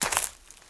STEPS Leaves, Walk 08.wav